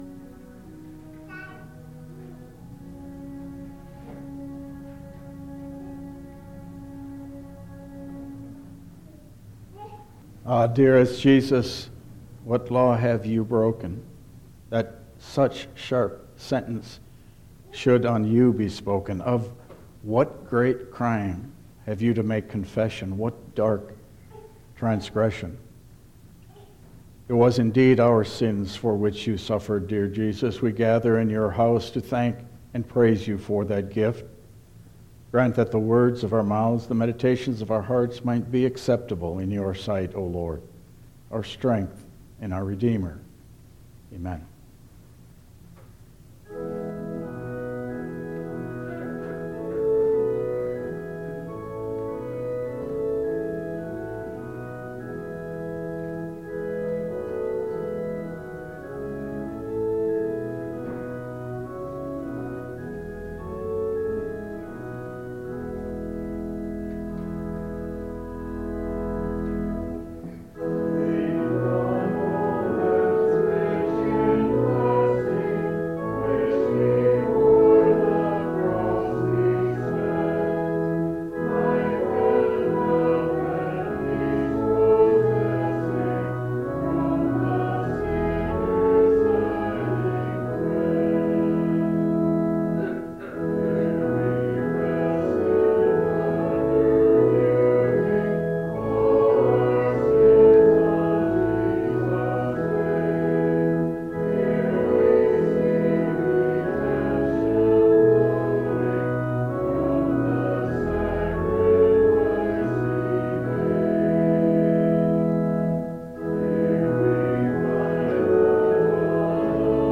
Passage: Matthew 27:19 Service Type: Lenten Service